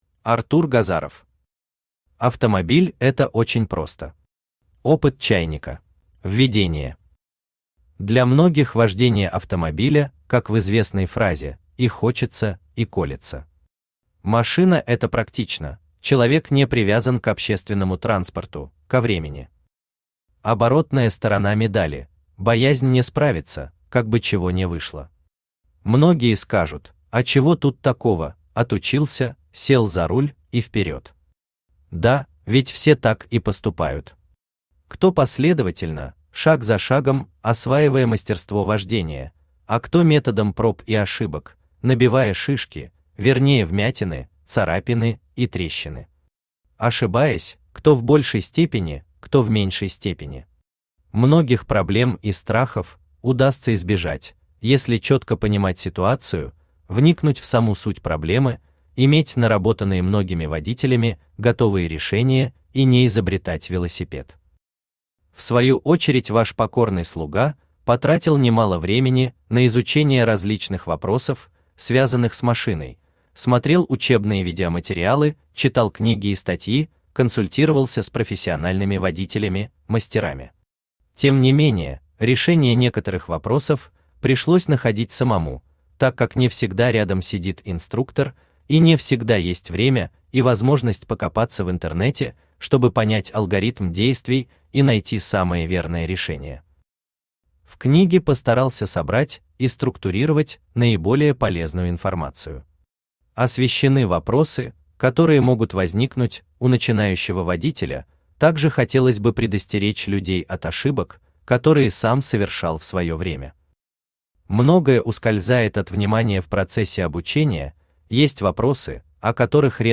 Аудиокнига Автомобиль это очень просто. Опыт «чайника» | Библиотека аудиокниг
Опыт «чайника» Автор Артур Юрьевич Газаров Читает аудиокнигу Авточтец ЛитРес.